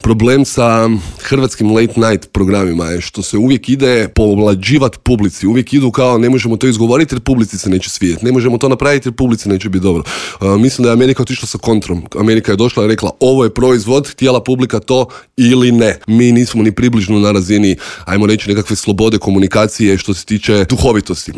ZAGREB - Novu sezonu intervjua na Media servisu otvorili smo laganom temom.